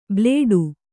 ♪ blēḍu